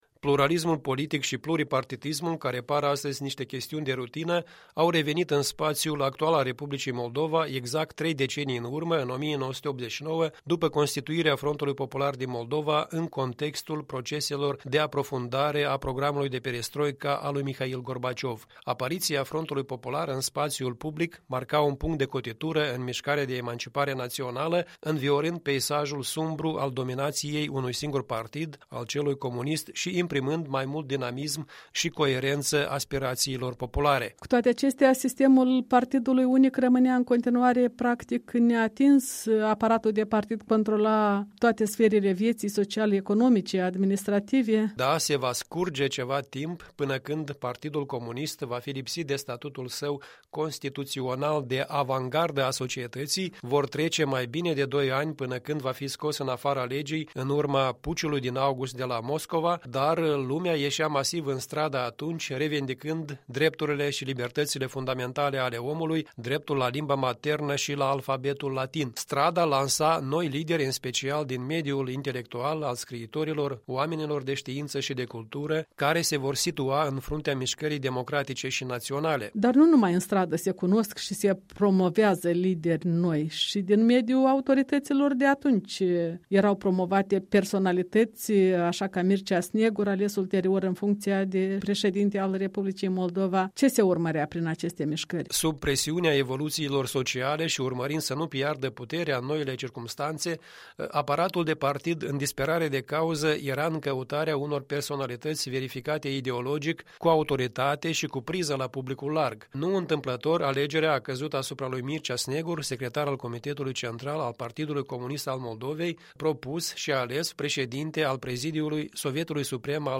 În vizita lui săptămânală la studioul EL din Chișinău